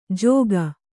♪ jōga